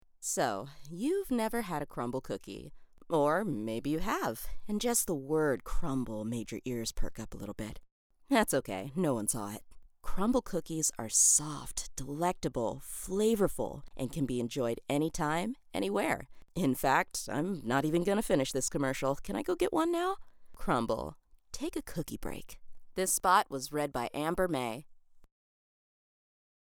Voiceover
Crumbl Ad